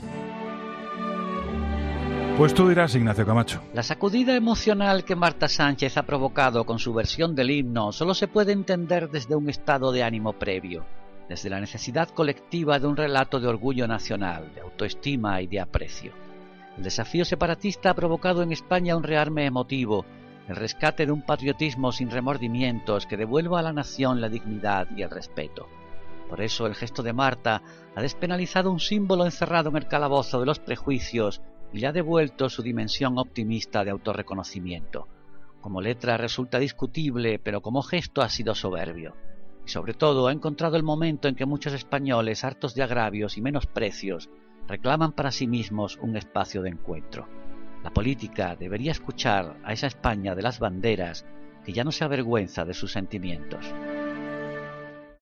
El comentario de Ignacio Camacho en 'La Linterna', sobre la letra de Marta Sánchez al himno español: